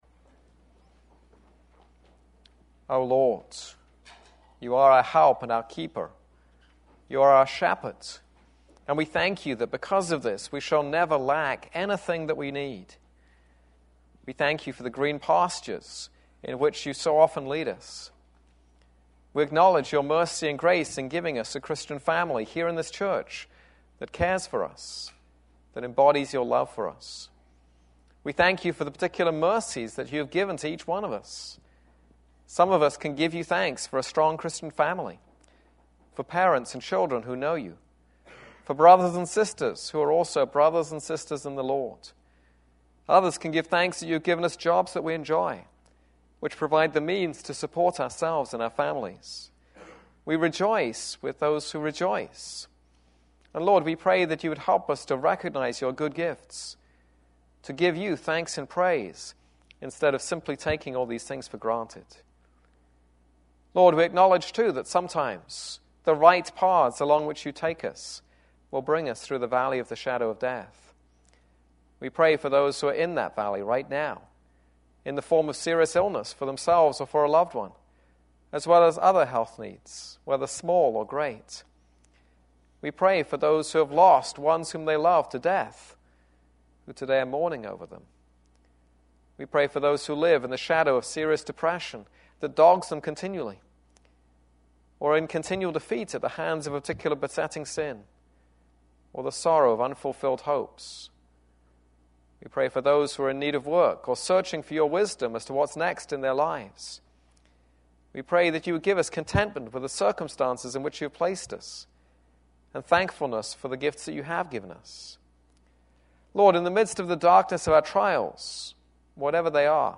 This is a sermon on Song of Songs 4:8-5:2.